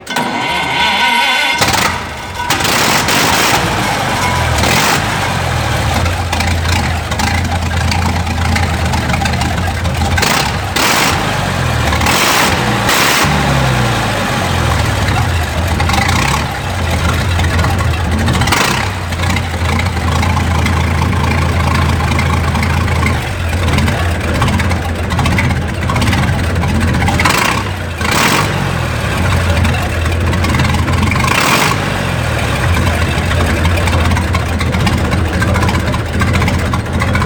Engine Sound
Six-cylinder, cast iron cylinders mounted on aluminum crankcase, updraft carburetor, triple combination distributor/battery/magneto ignition system, T-head valvle arrangment, 75 hp, 572.6 CID
1915-ALF-Rhino-engine.mp3